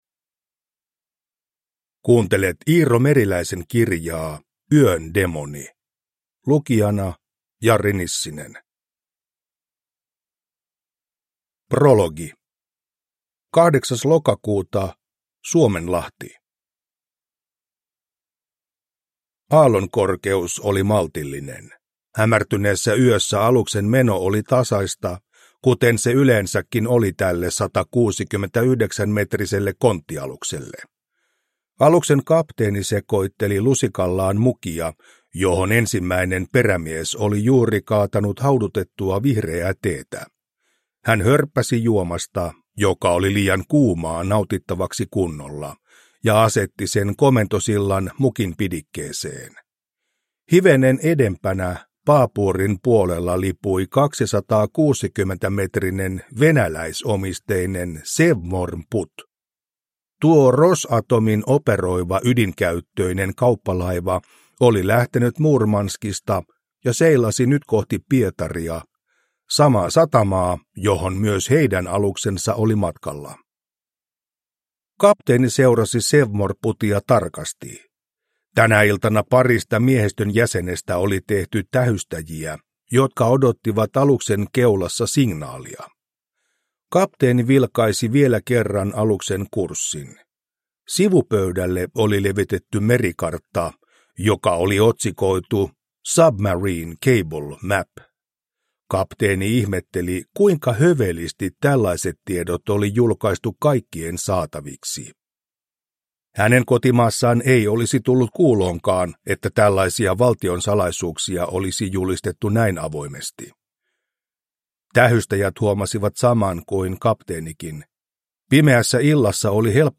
Yön demoni – Ljudbok